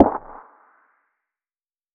SNARE - STILL HERE.wav